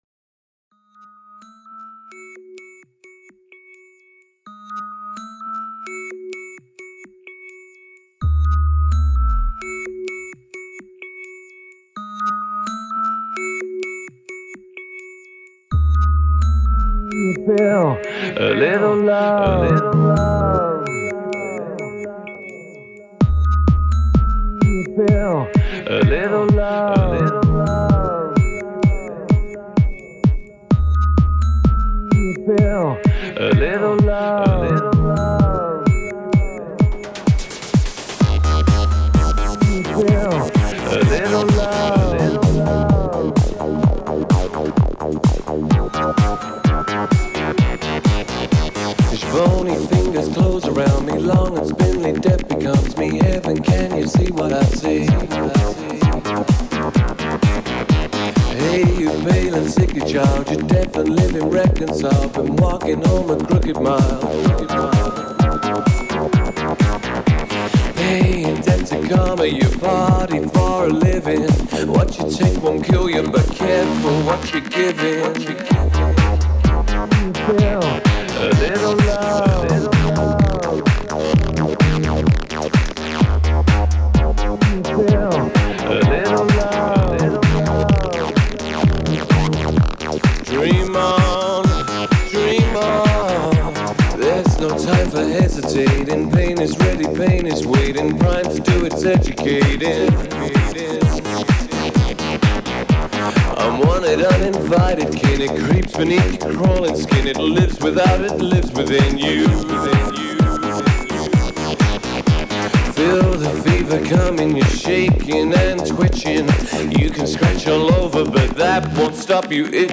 remix
It's definitely cheesy dancefloor ebm